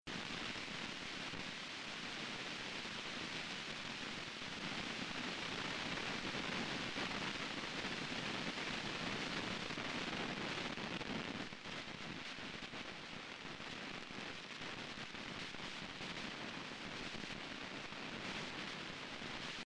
Hey I have an M-Audio Projectmix I/O with a preamp that has some real nasty static on channel 6 ALL THE TIME!
I have attached a short MP3 of the static sound.